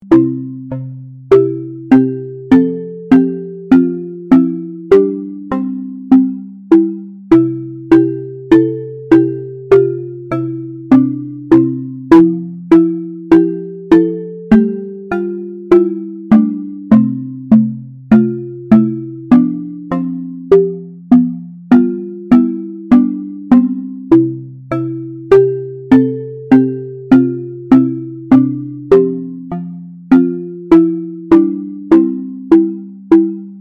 環境ゲームとかにあいそうなイメージです。ループ対応。
BPM100